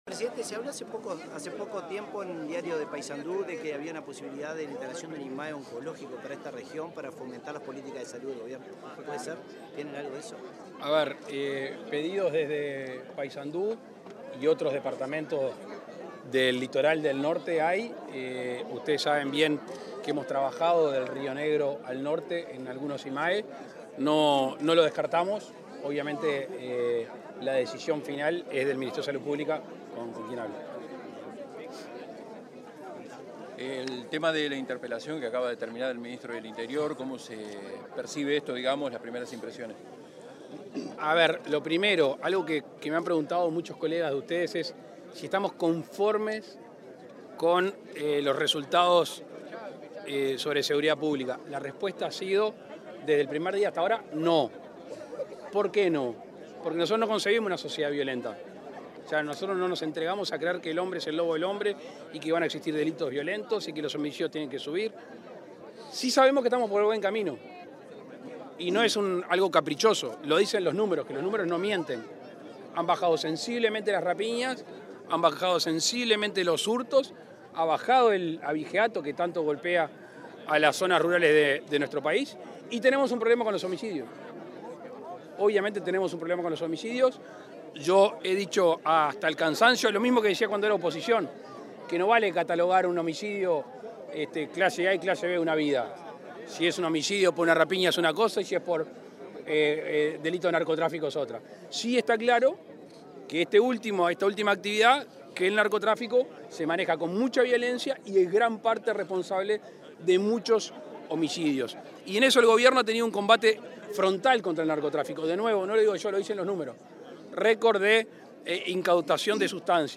Declaraciones del presidente Lacalle Pou a la prensa
El presidente Luis Lacalle Pou dialogó con la prensa en Paysandú luego de inaugurar la rotonda de ingreso de la localidad de Quebracho.